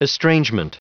Prononciation du mot estrangement en anglais (fichier audio)
Prononciation du mot : estrangement